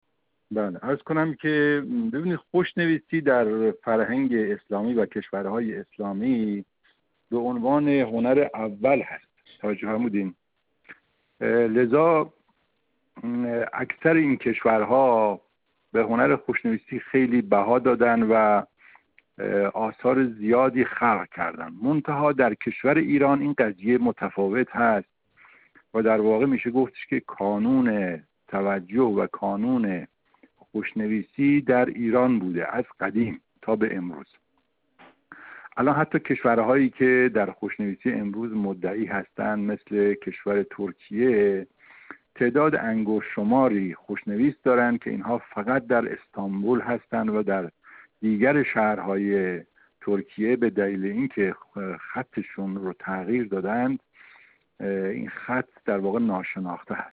در گفت‌و‌گو با ایکنا